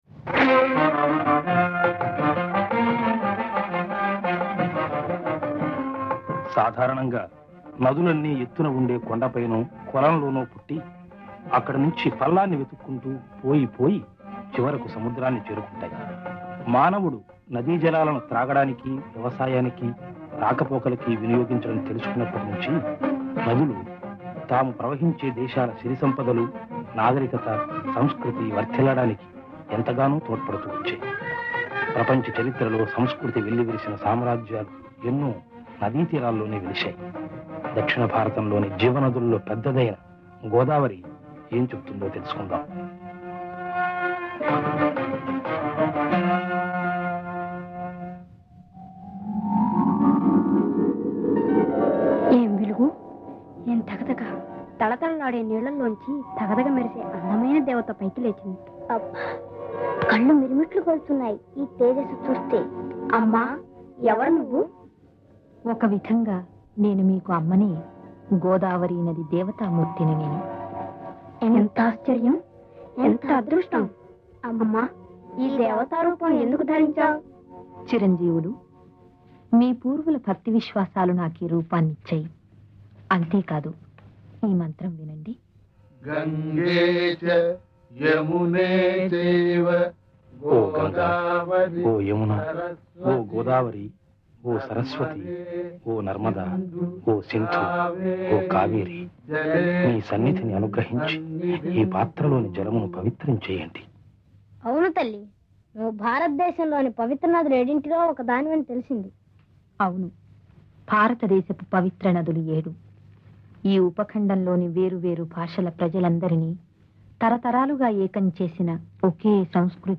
ఈ సంగీత రూపకానికి ఒక ప్రత్యేకత ఉంది.